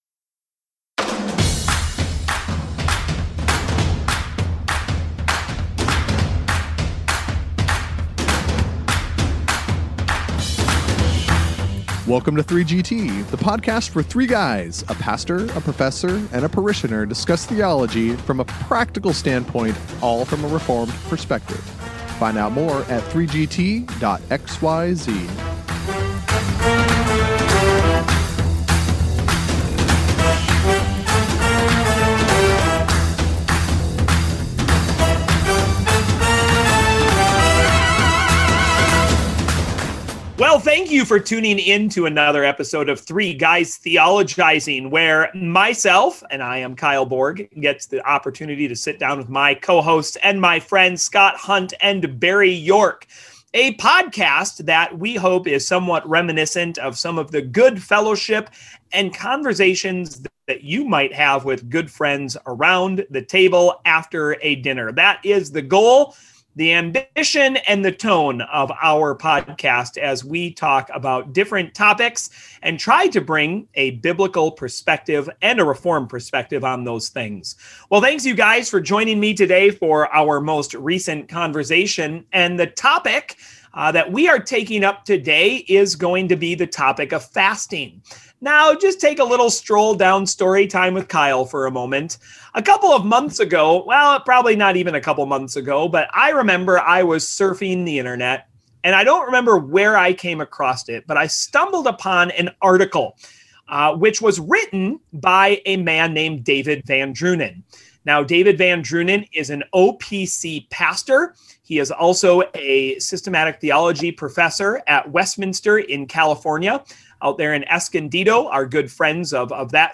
So join another lively conversation that the guys hope you will find “fast moving” on this episode of 3GT!